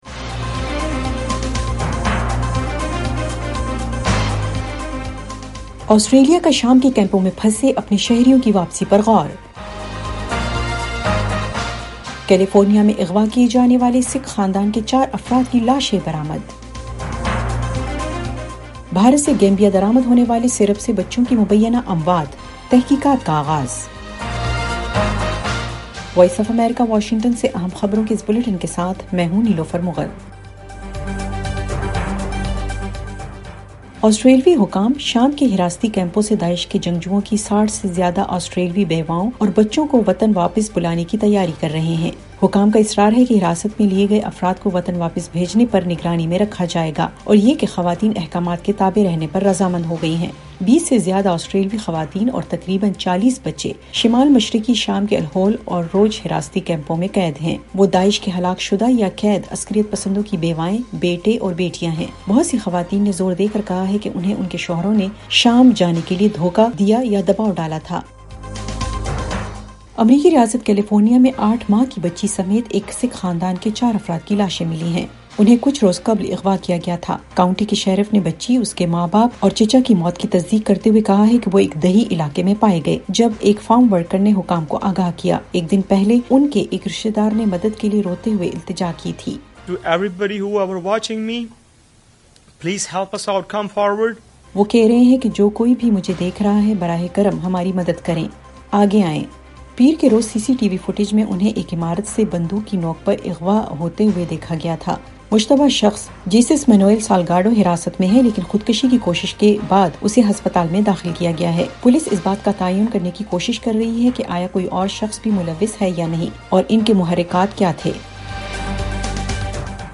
ایف ایم ریڈیو نیوز بلیٹن : رات 8 بجے